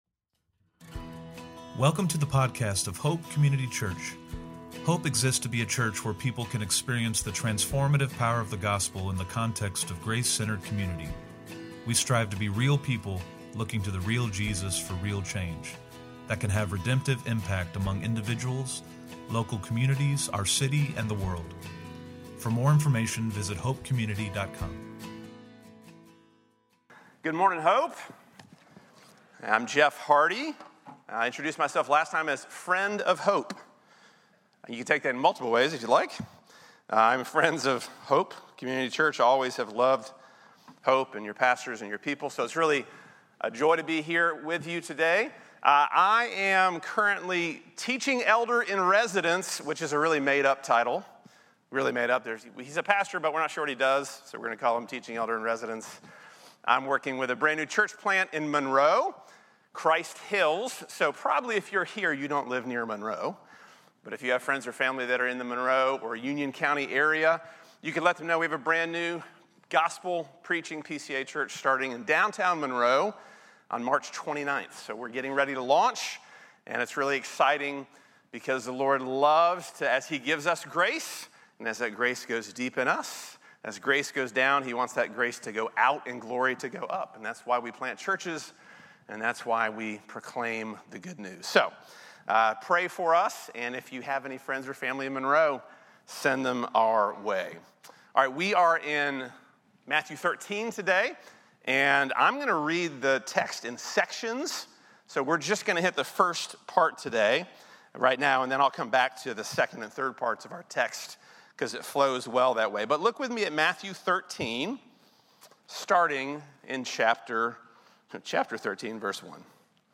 A message from the location "Cotswold."